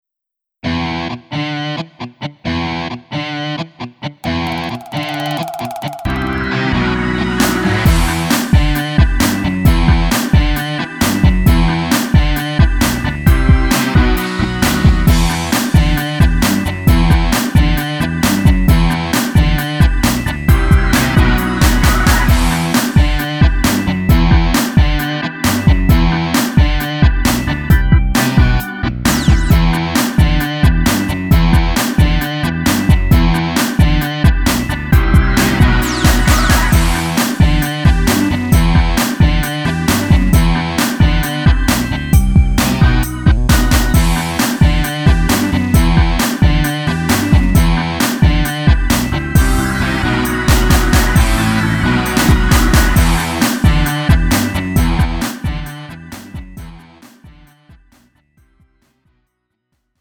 음정 -1키 3:30
장르 가요 구분 Lite MR